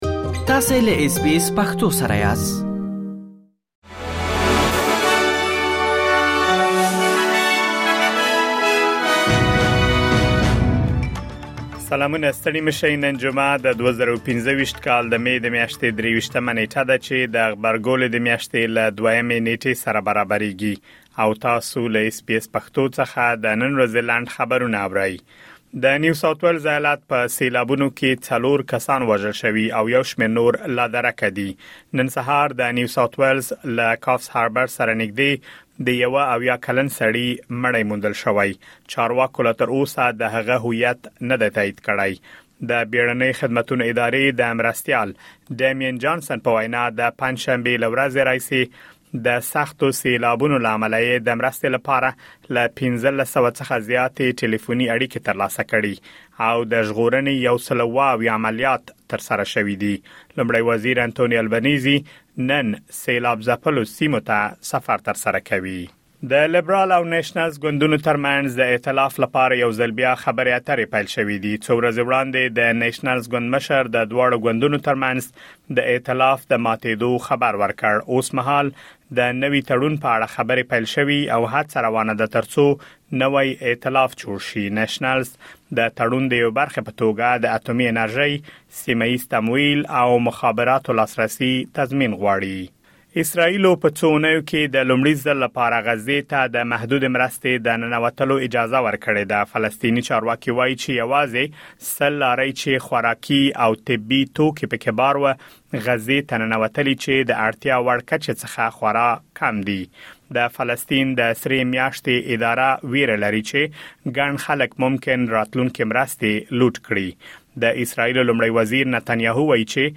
د اس بي اس پښتو د نن ورځې لنډ خبرونه | ۲۳ مې ۲۰۲۵
د اس بي اس پښتو د نن ورځې لنډ خبرونه دلته واورئ.